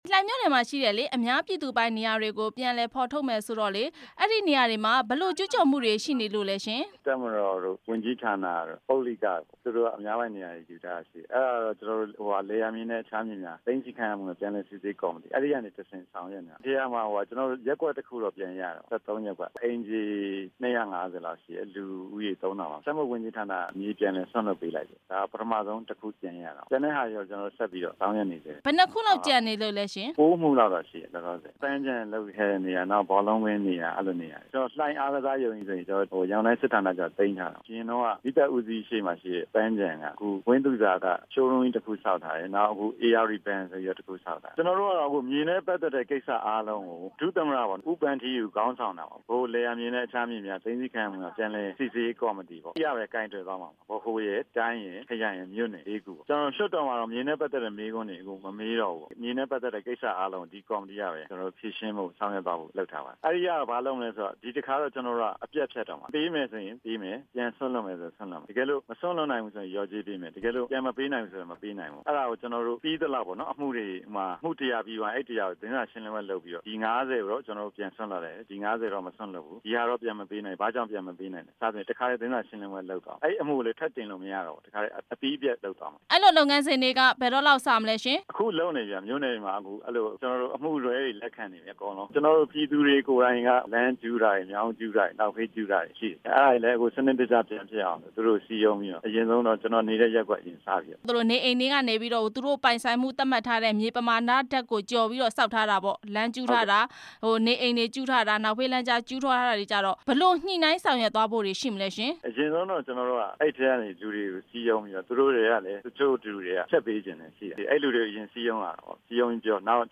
အများပြည်သူပိုင်မြေတွေကို ပြန်ပေးဖို့ မေးမြန်းချက်